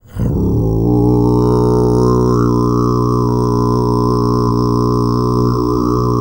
TUV11 DRON01.wav